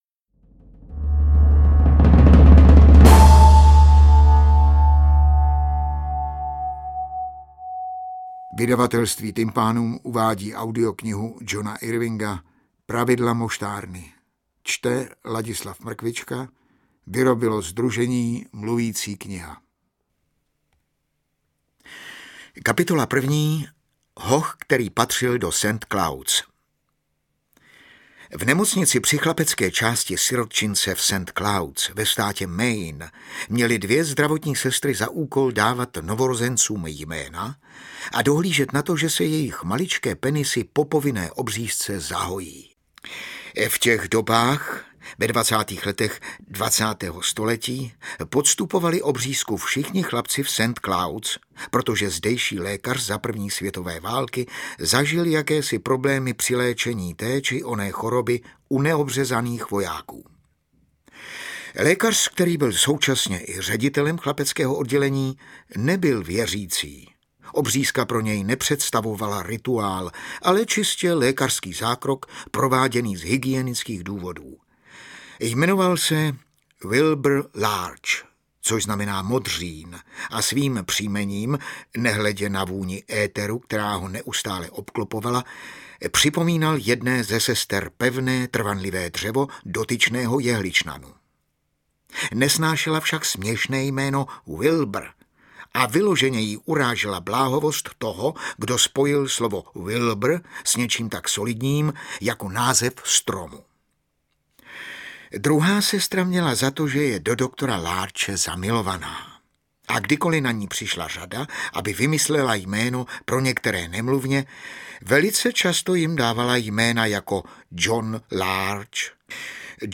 Interpret:  Ladislav Mrkvička
AudioKniha ke stažení, 164 x mp3, délka 28 hod. 25 min., velikost 1943,4 MB, česky